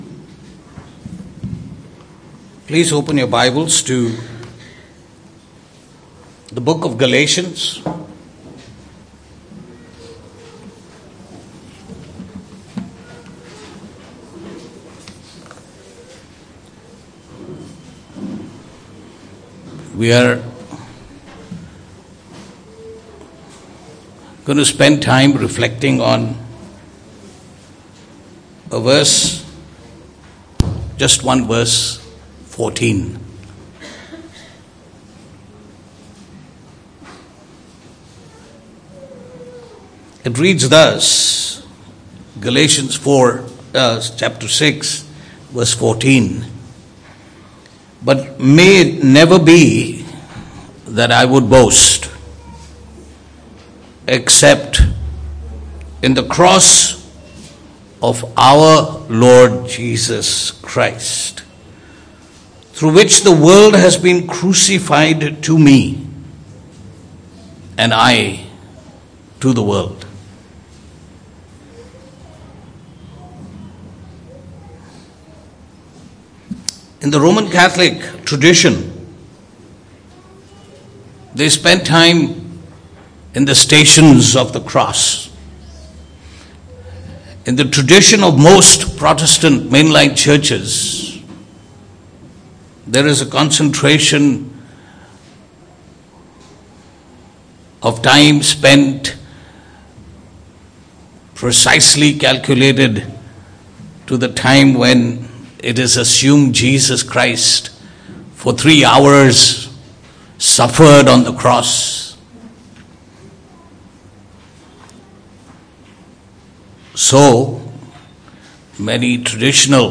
Passage: Galatians 6:14 Service Type: Good Friday « Submission